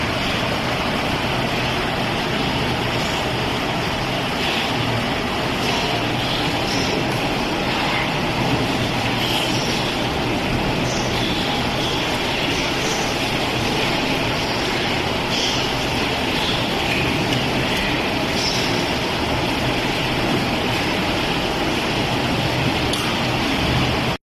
Así descargaba la tormenta en la provincia de Jaén